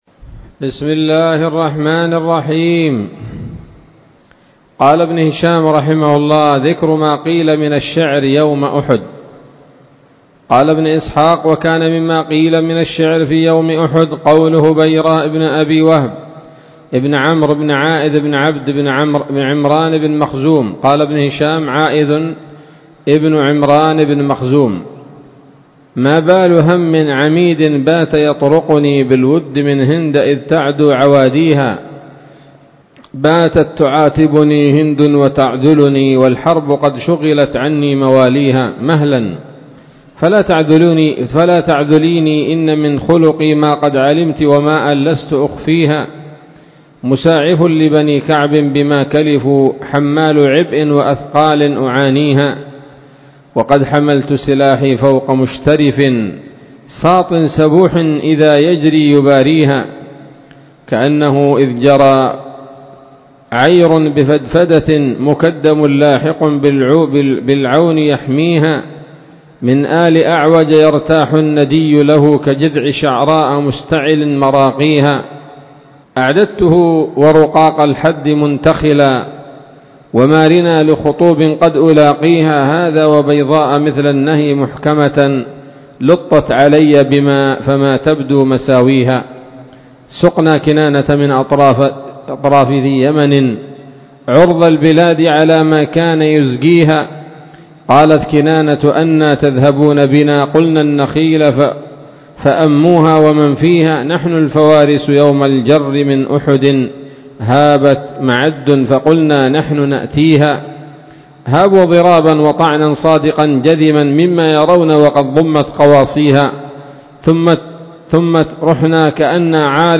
الدرس السابع والسبعون بعد المائة من التعليق على كتاب السيرة النبوية لابن هشام